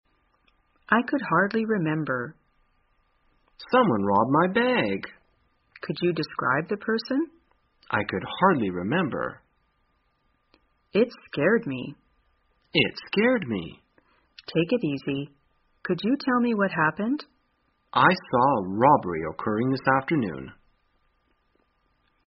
在线英语听力室生活口语天天说 第192期:怎样表述抢劫案的听力文件下载,《生活口语天天说》栏目将日常生活中最常用到的口语句型进行收集和重点讲解。真人发音配字幕帮助英语爱好者们练习听力并进行口语跟读。